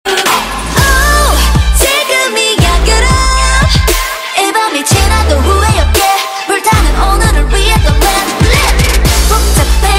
FM_synth_loop_serum_string_100_D#m_v2